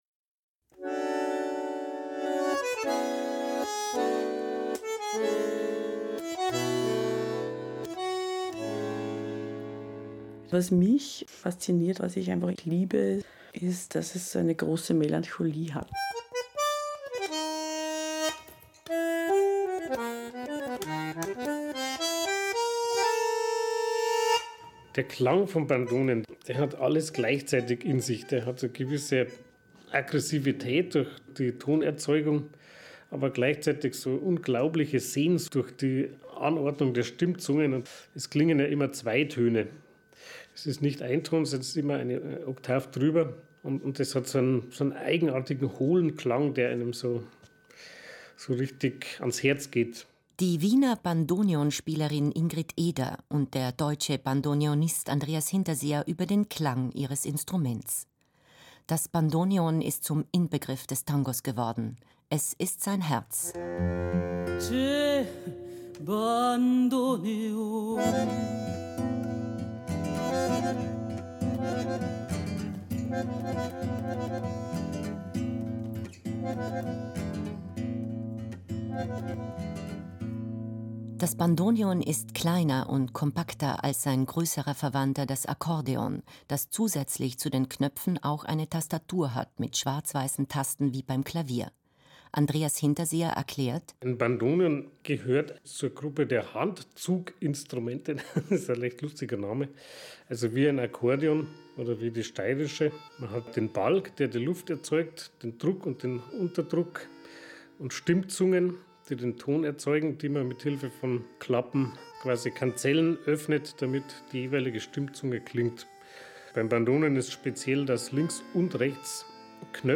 Eine 4-teilige Radiosendung über den Tango Argentino in Europa. Über seine Geschichte, über die Gegenwart, über das Bandoneon und den Tanz, mit MusikerInnen, Dirigenten, Tanzenden, DJ’s und Musikwissenschaftlerinnen und Ethnomusikologinnen im Gespräch.